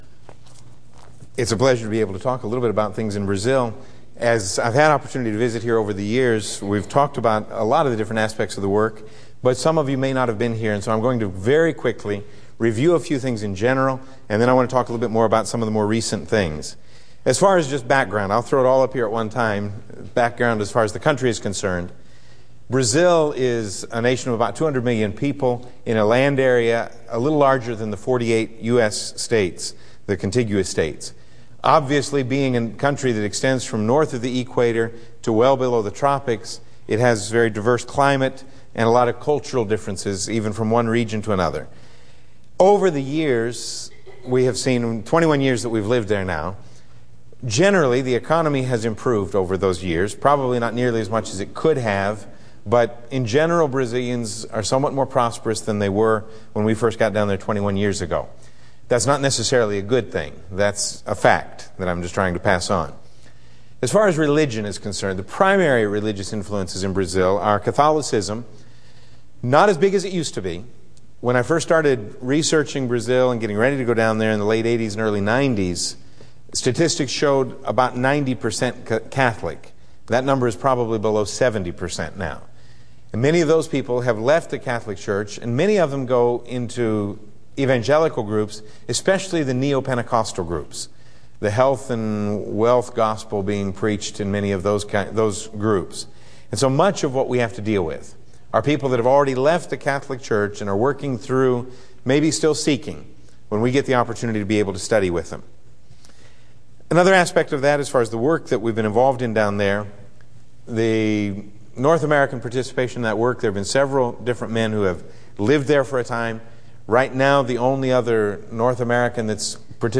Service: Wed PM Type: Sermon